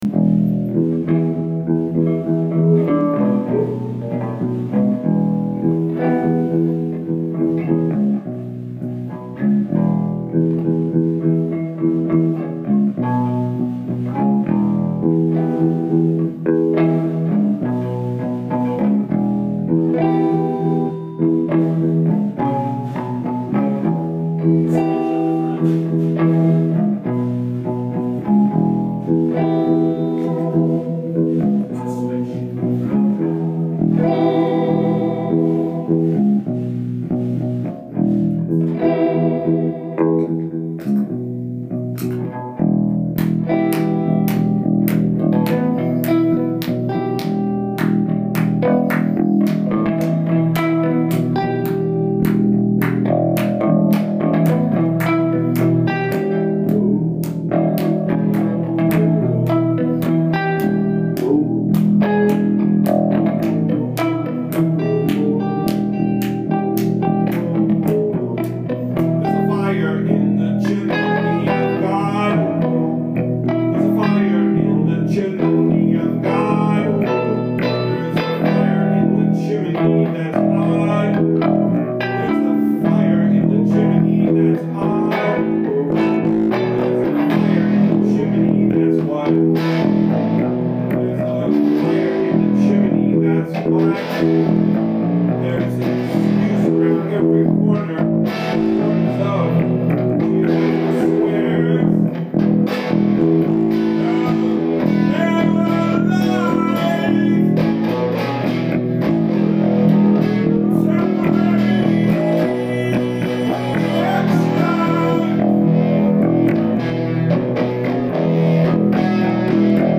ALL MUSIC IS IMPROVISED ON SITE
voice
guitar
flute
bass